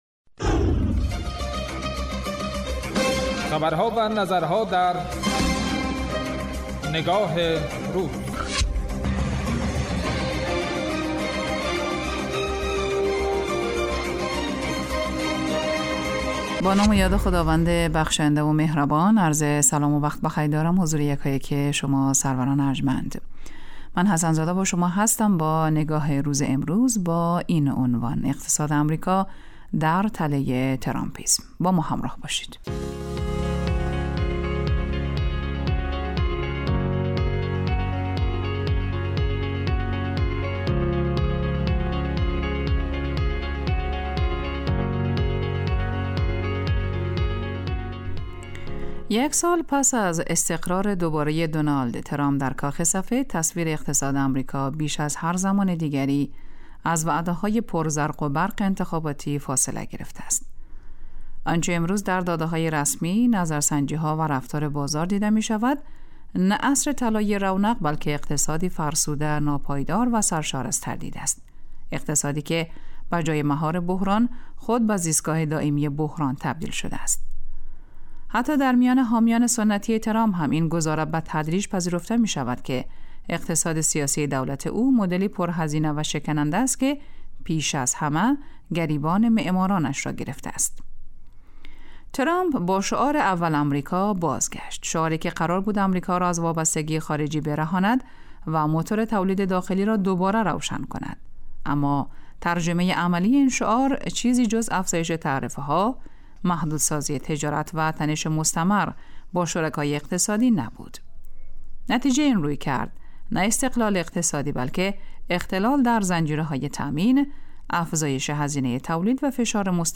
اطلاع رسانی و تحلیل و تبیین رویدادها و مناسبت‌های مهم، رویکرد اصلی برنامه نگاه روز است که روزهای شنبه تا پنج‌شنبه ساعت 13:00 به مدت 10 دقیقه پخش می‌شود.